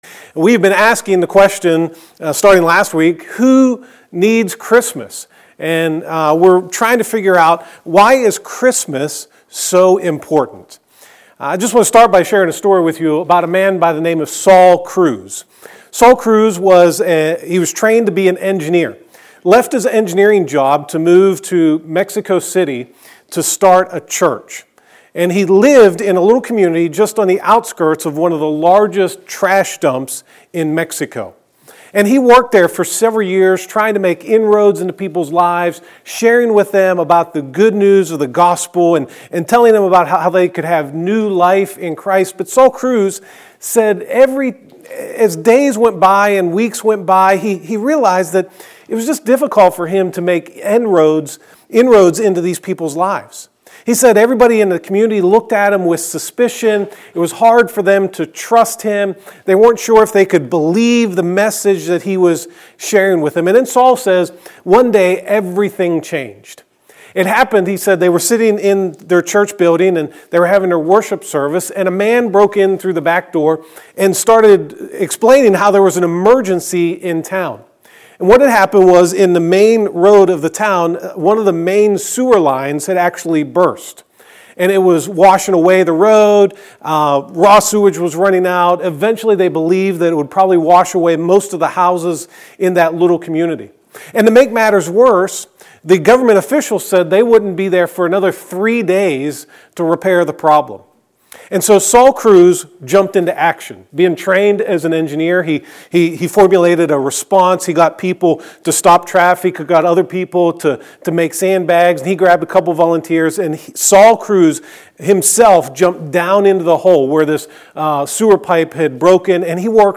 God Does Preacher